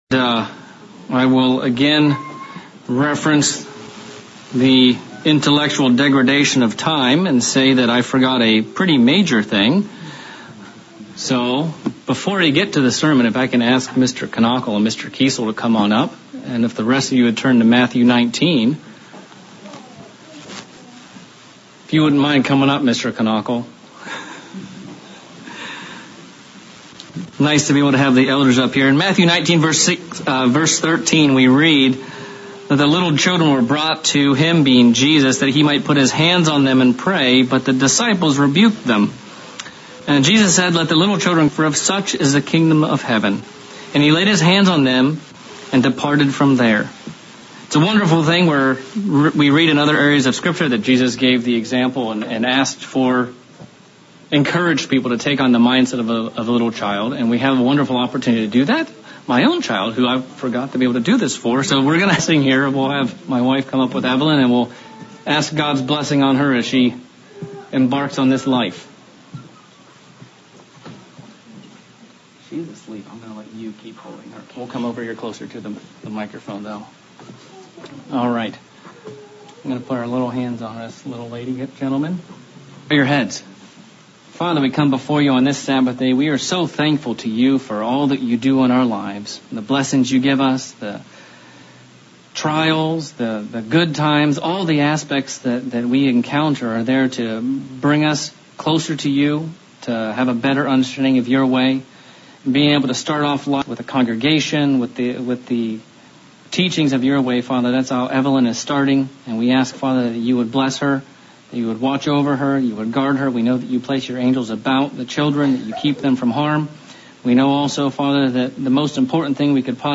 A lot can be gleaned from the story surrounding the birth of Jesus Christ. This sermon explores some of the background to His birth regarding its location and a few details about His parents to begin scratching the surface of what God brought together through the birth of His Son.